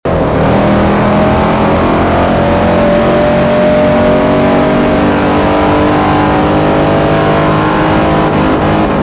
Index of /server/sound/vehicles/tdmcars/69camaro